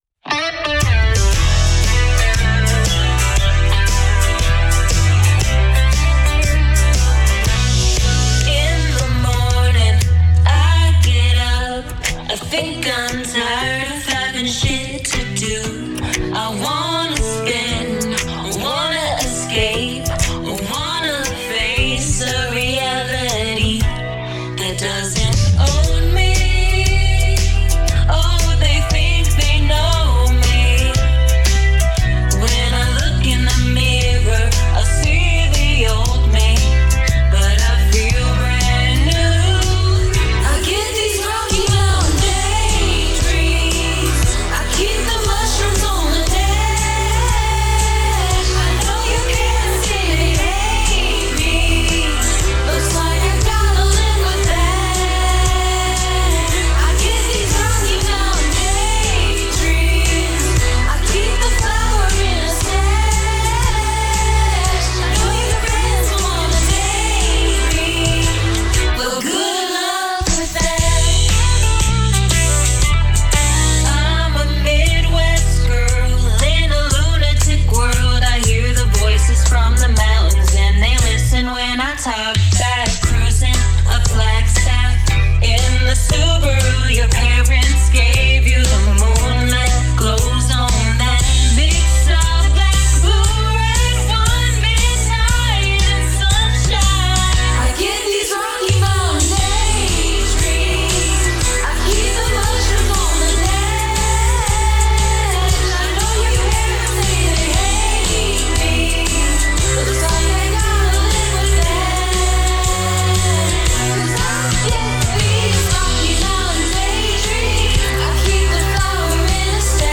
country, electronic song